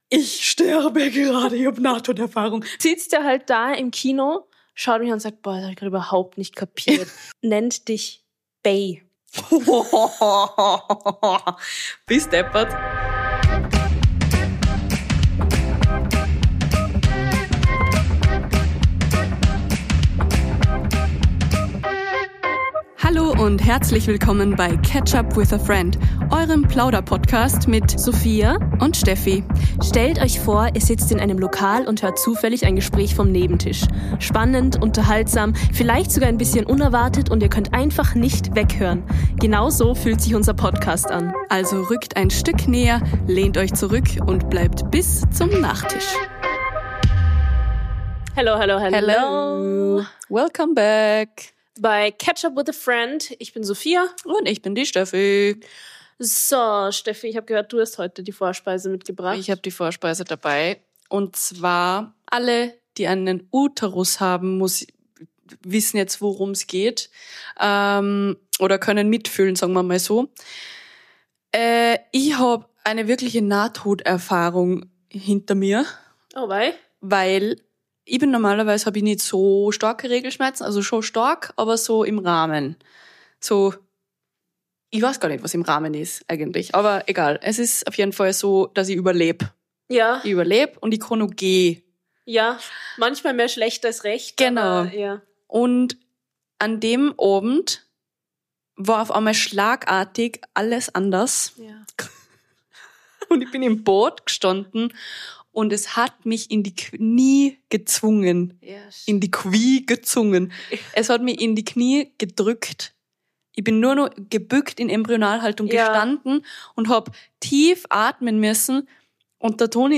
Dazwischen gibt’s ehrliche Anekdoten, ein paar Lacher - und Studiokater Aki, der uns immer mal wieder charmant vom Thema ablenkt. Und am Ende: Red Flags.